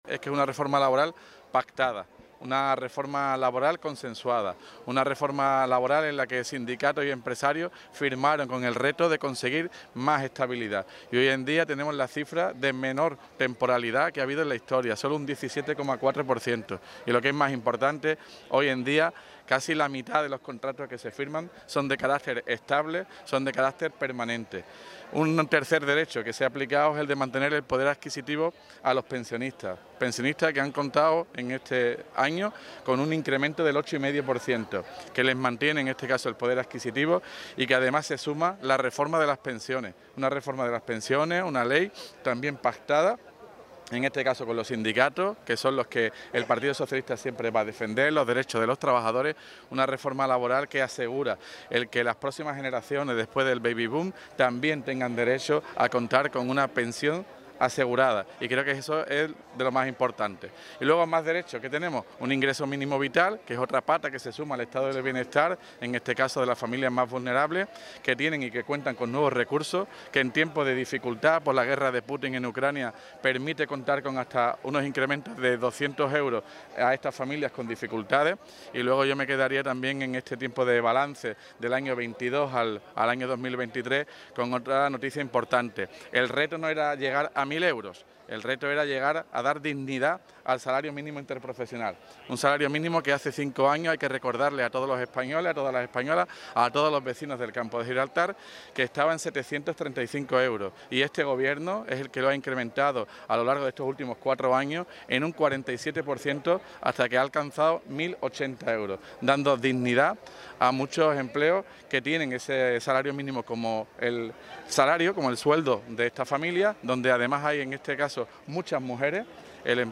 MANIFESTACION_1_MAYO_TOTAL_ALCALDE.mp3